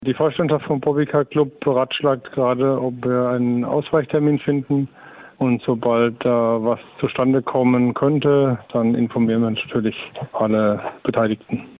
Interview: Warum wird das Bobby Car Rennen in Ostheim abgesagt?